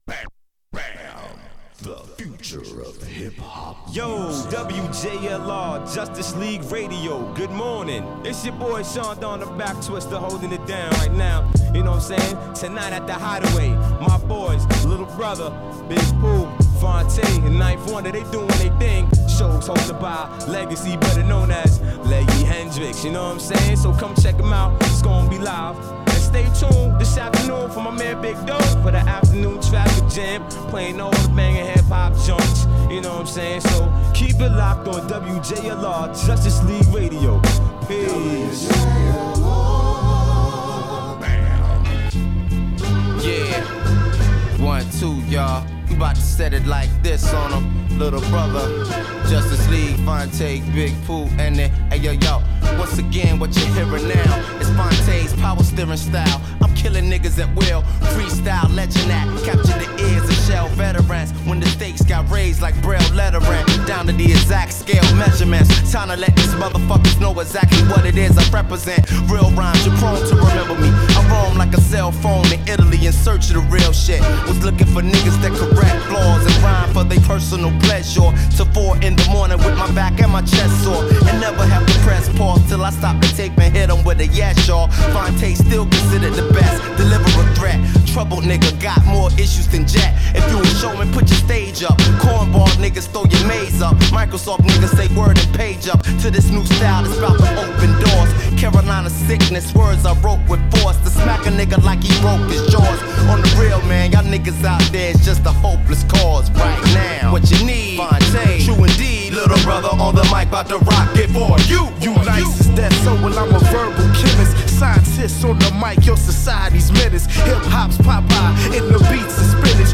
Hip Hop Rap